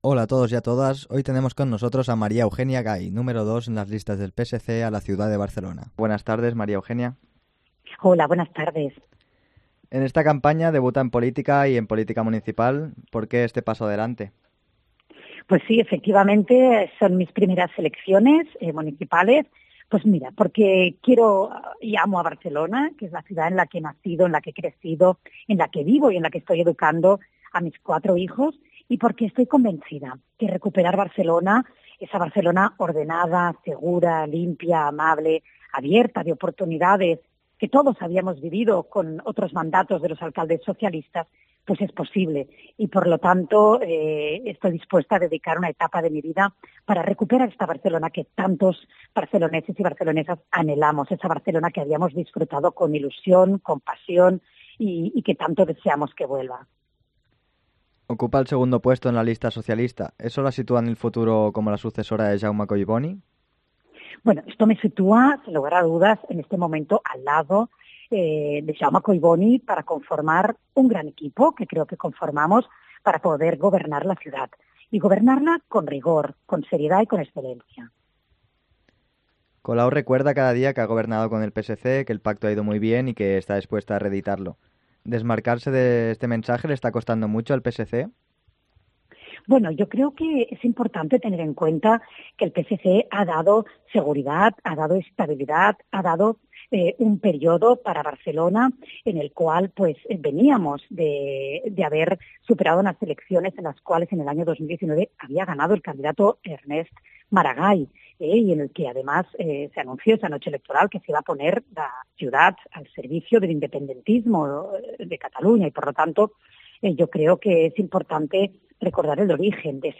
Entrevista a Maria Eugènia Gay, número dos de la candidatura del PSC en Barcelona